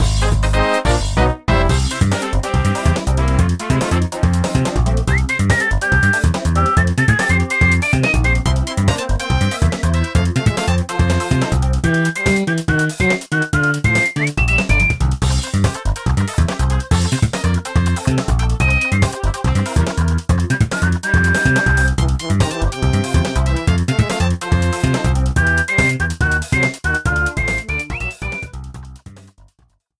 Reduced length to 30 seconds, with fadeout.